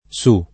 [ S u ]